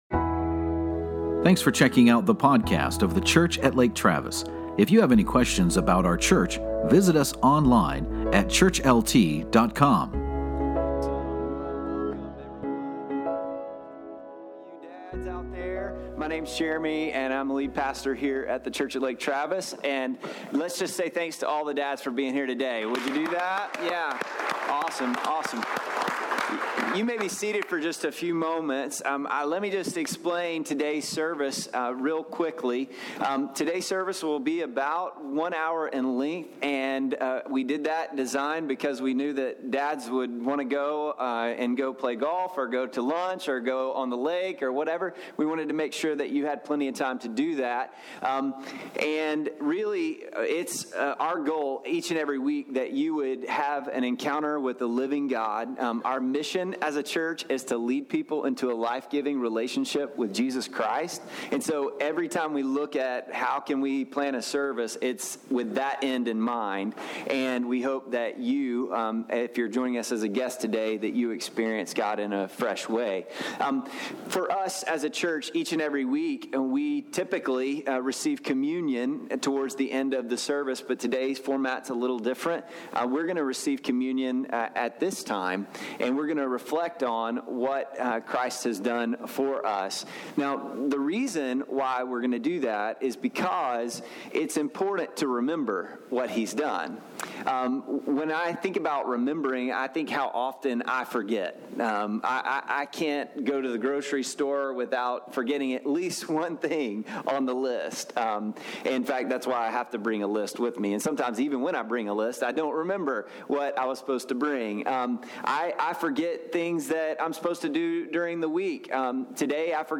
While we covered a brief devotional thought regarding communion today, we had a less traditional message today, that we think your friends and family members may enjoy hearing about. God bless you as you lean in to learn from 3 men who love Jesus and love their families well.